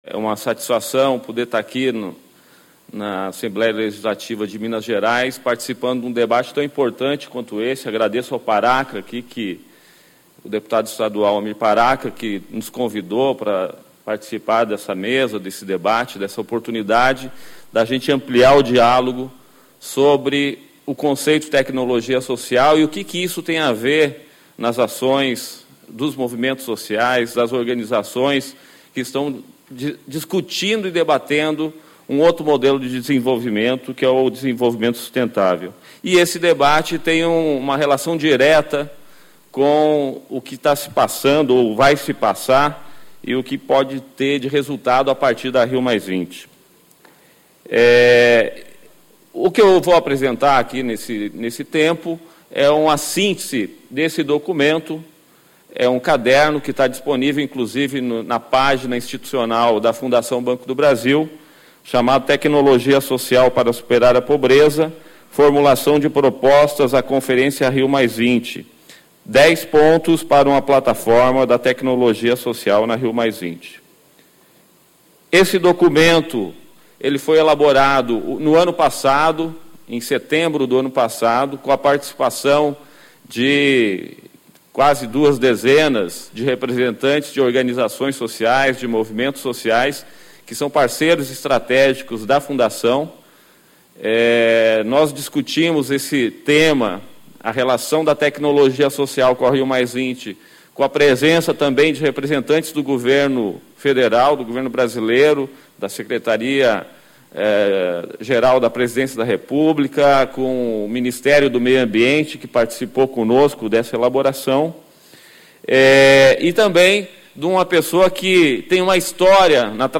Rumo à Rio + 20 e à Cúpula dos Povos - Ciclo de Debates
Discursos e Palestras